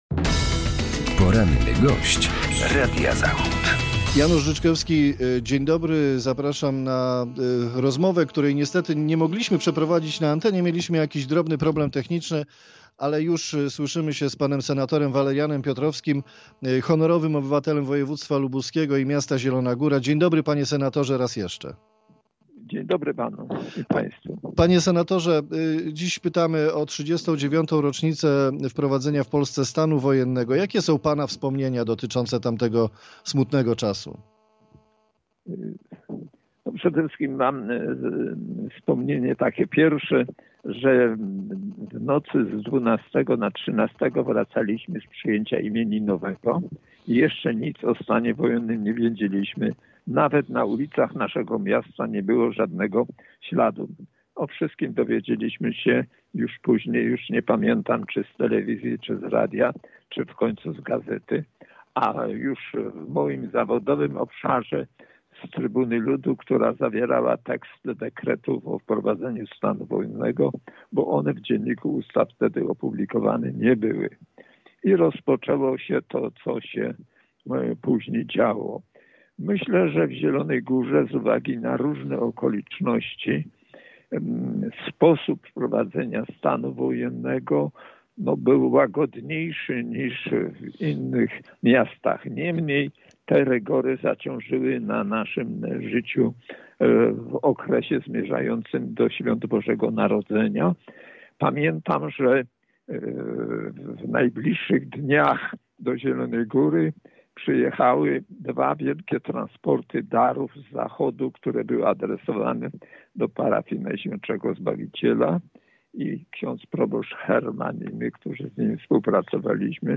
Z senatorem, Honorowym Obywatelem woj. lubuskiego i miasta Zielona Góra rozmawiał